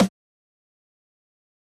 Losers Snare.wav